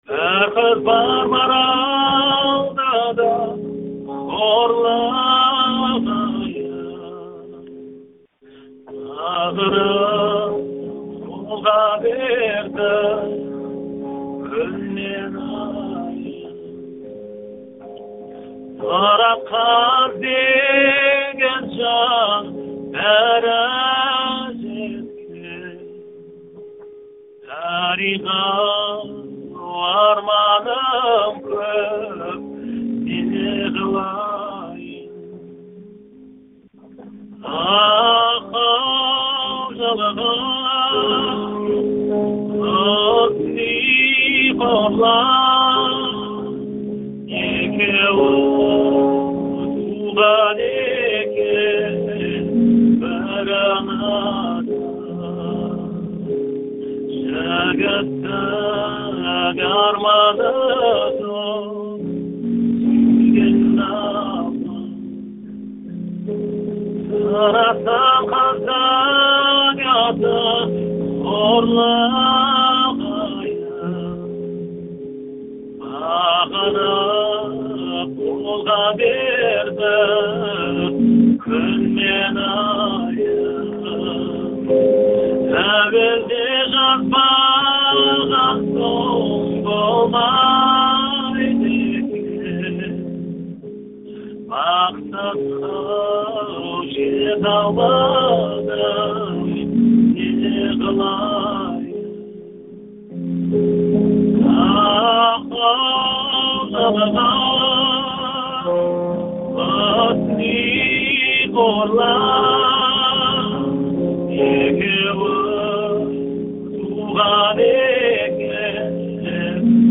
Батырхан Азаттыққа "Құсни Қорлан" әнін айтып берді